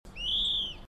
Call recorded Encarnación, Departamento Itapúa